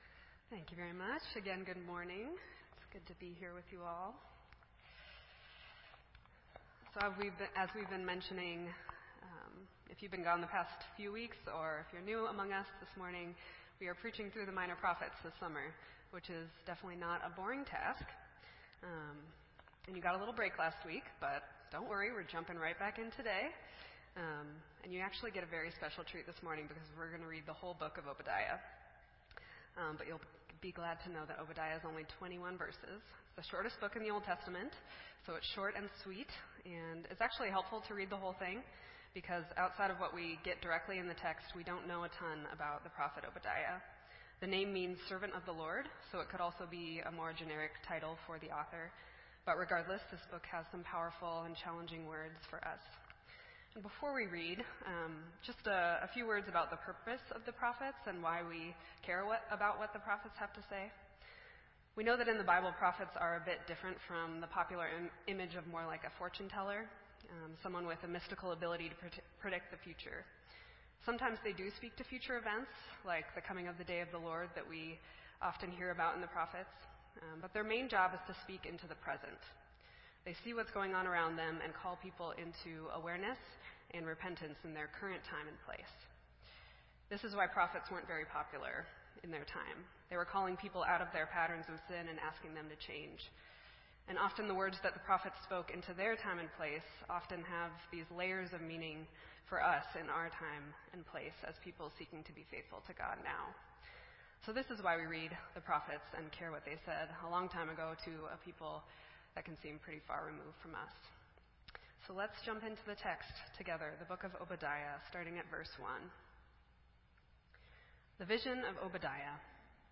This entry was posted in Sermon Audio on July 5